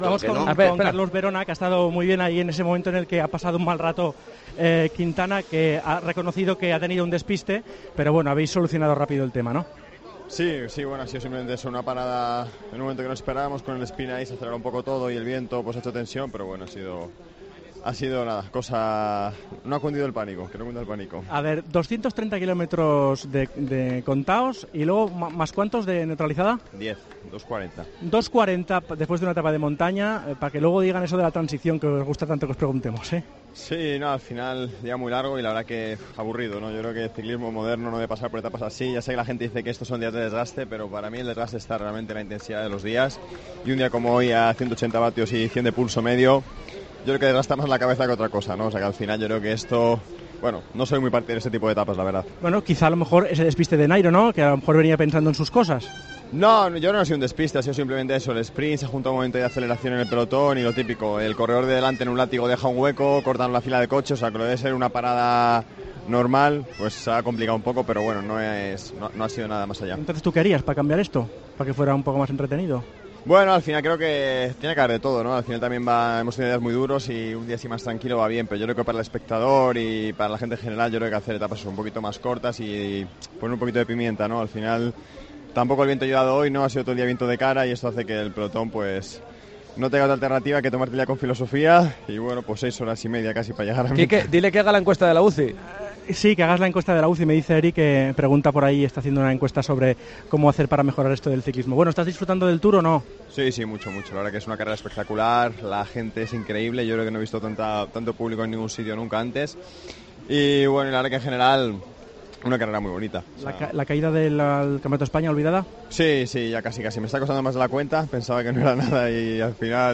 "Hay que hacer etapas más cortas y con un poco más de pimienta para el espectador", dice el corredor del Movistar Team.